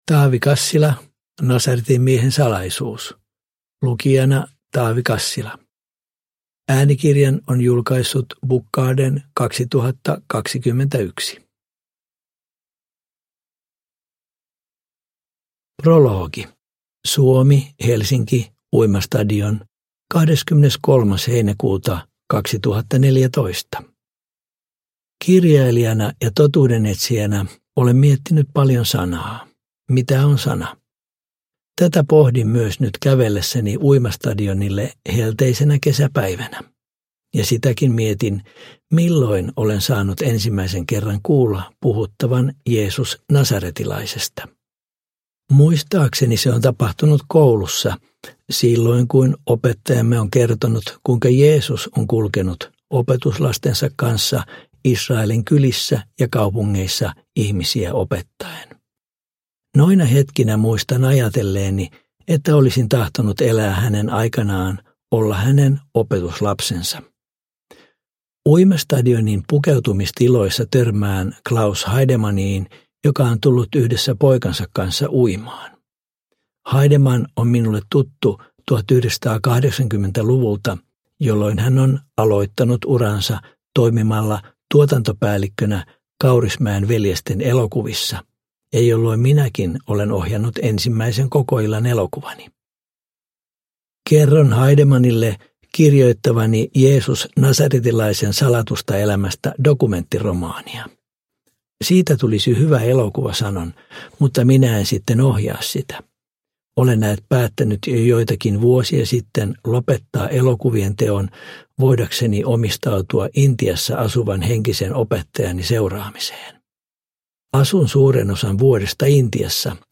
Nasaretin miehen salaisuus – Ljudbok – Laddas ner